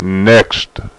Download a high-quality next sound effect.